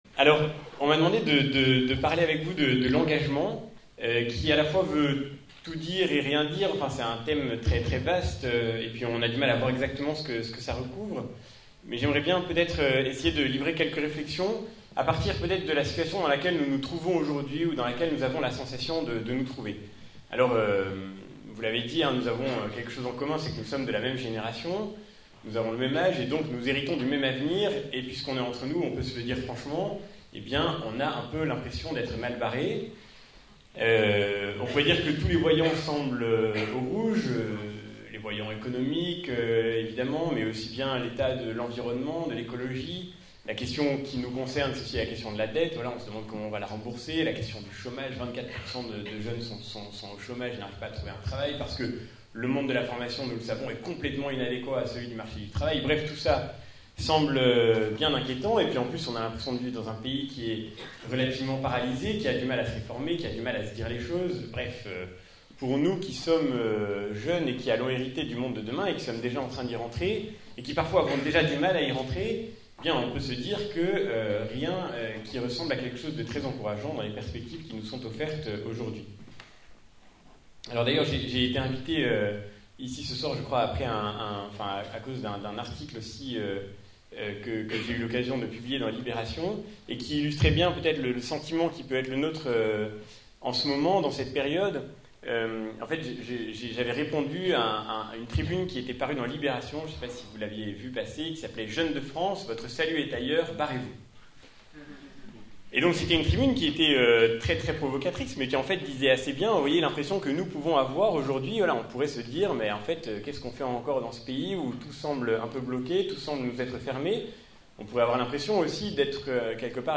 Télécharger la conférence de Francois-Xavier Bellamy